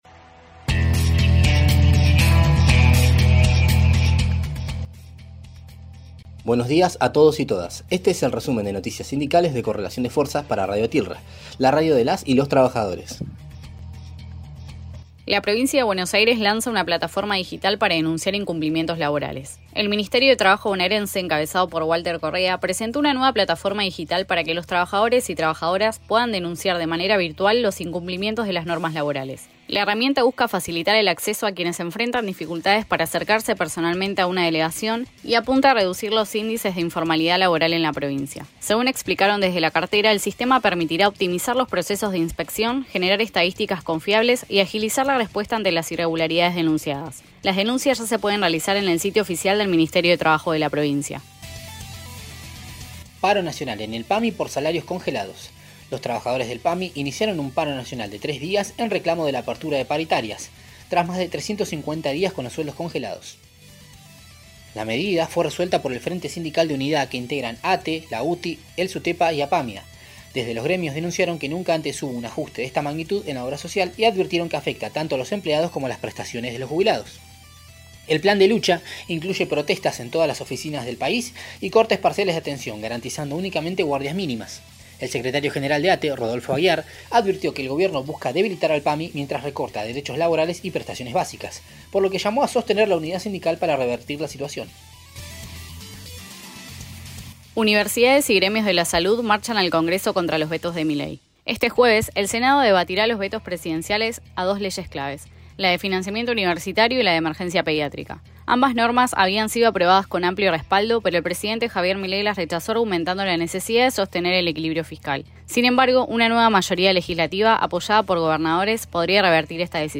Resumen de Noticias Sindicales